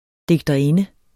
Udtale [ degdʌˈenə ]